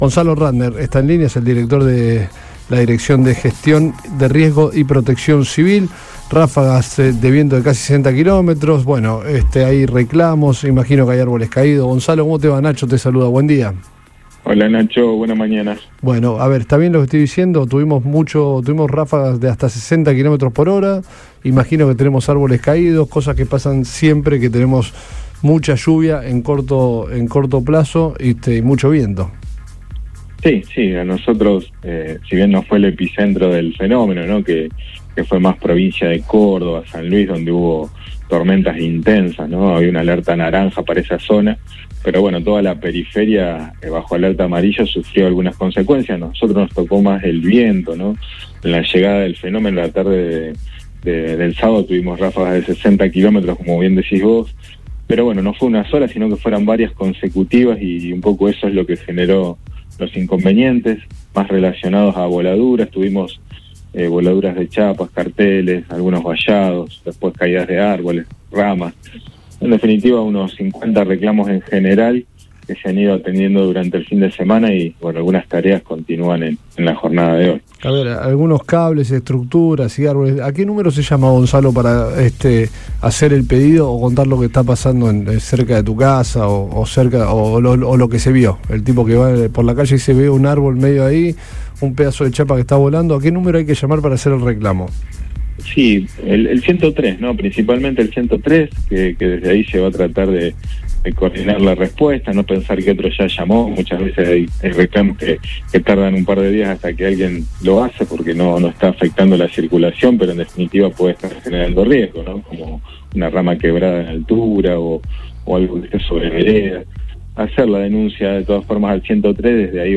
Gonzalo Ratner, director de la Dirección de Gestión de Riesgo y Protección Civil, analizó en el programa Antes de Todo de Radio Boing las consecuencias del fuerte viento que afectó a la ciudad durante el fin de semana.